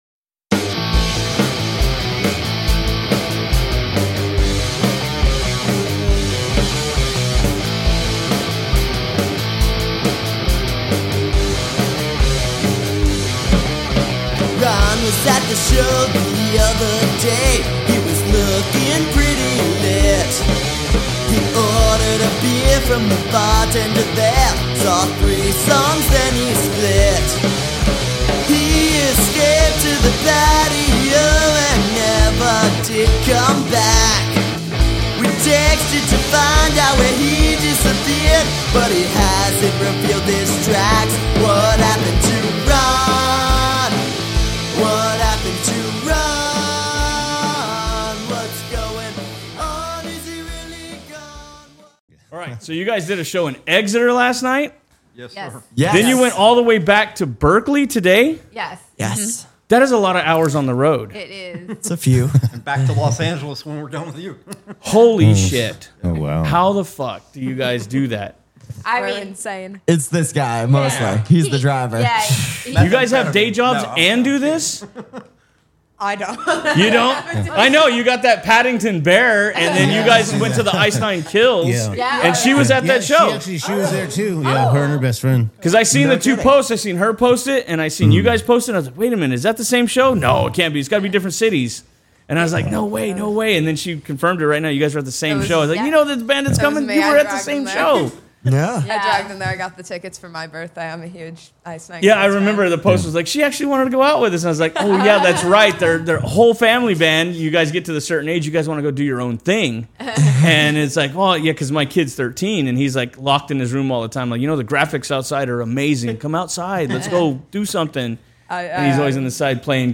We had a kick ass interview with a band from Los Angeles called The Cheesebergens.